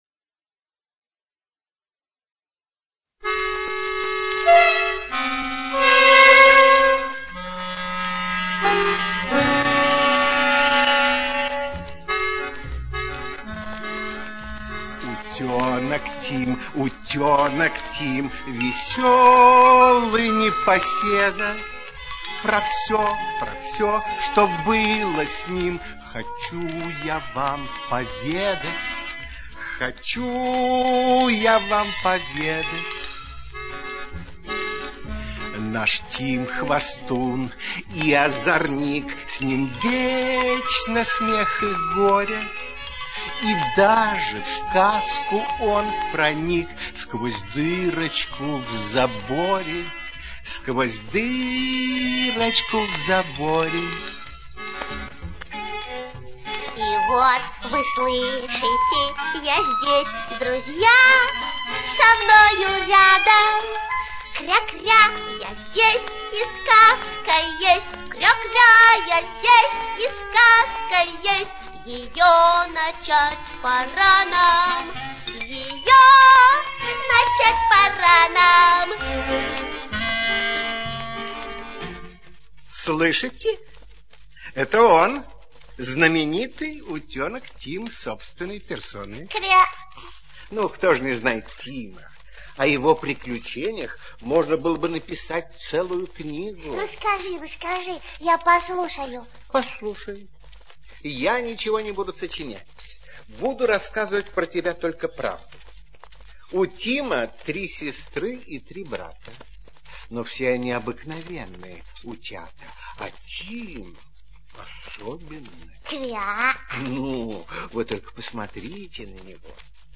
Знаменитый утенок Тим - аудиосказка Блайтона - слушать онлайн
Текст читают: Сергачев В. и Плятт Р.